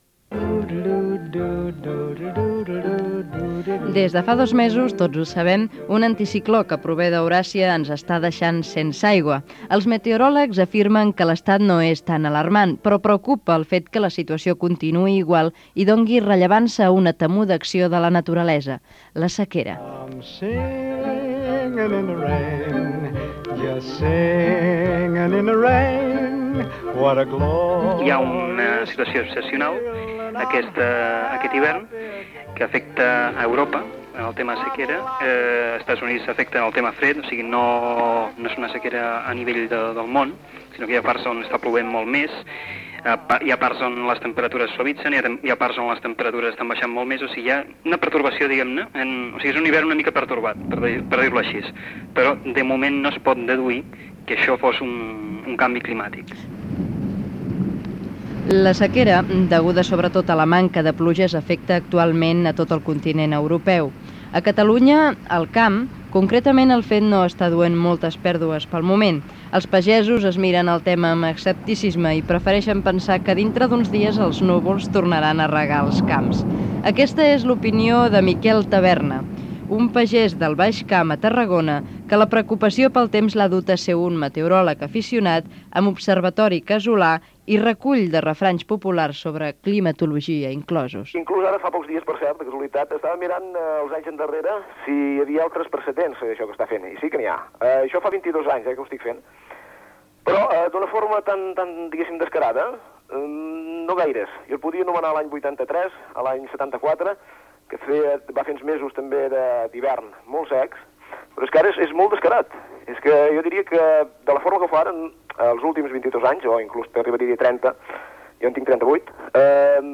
Reportatge sobre els dos mesos de sequera i el seu efecte en l'agricultura. El canvi climàtica
Informatiu